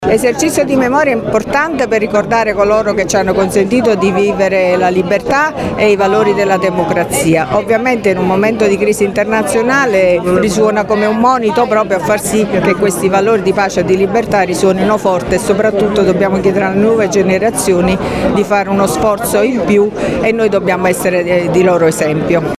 Si sono tenute questa mattina, presso il Monumento ai Caduti di Borgo Sabotino, a Latina, le celebrazioni per il 25 Aprile, Festa della Liberazione d’Italia di cui quest’anno ricorre l’81esimo anniversario.
A seguire le parole della Sindaca di Latina Matilde Celentano, e della Prefetta Vittoria Ciaramella.